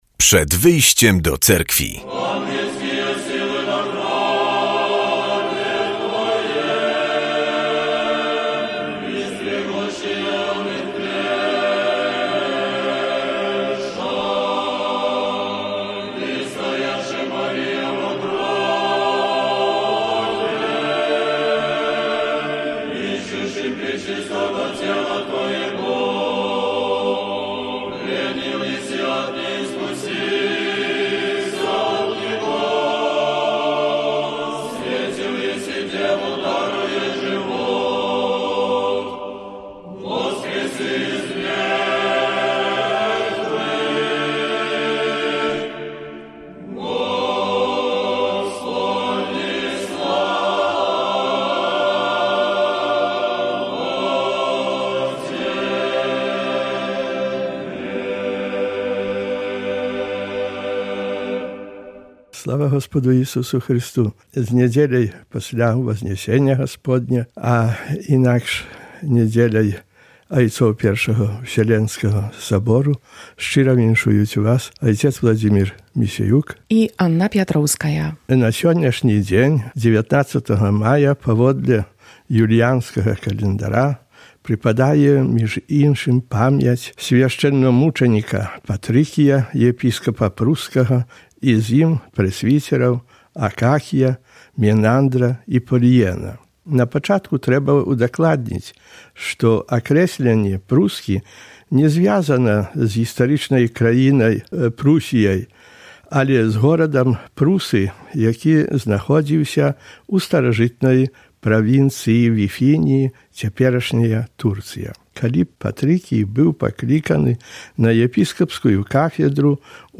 W audycji usłyszymy kazanie na temat niedzielnej Ewangelii i informacje z życia Cerkwi prawosławnej. Święte Źródło, "Krynoczka", to jedno z najważniejszych miejsc Prawosławia na Podlasiu, uświęcone modlitwą od XIII w. Dziś odbędzie się tam uroczyste poświęcenie cerkwi